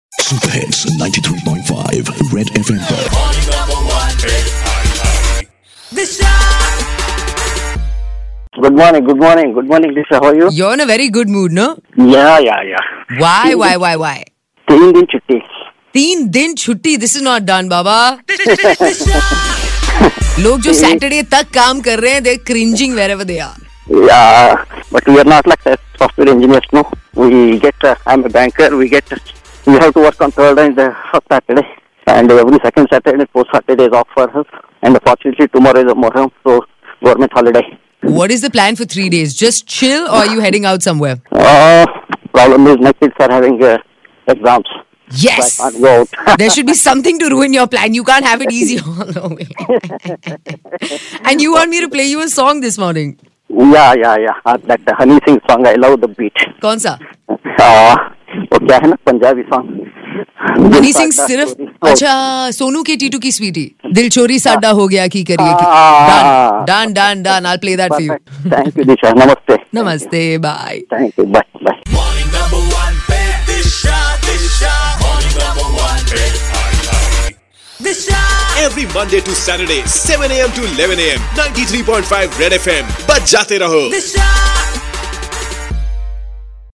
A listener called to share his weekend plans!